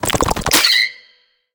Sfx_creature_penguin_skweak_02.ogg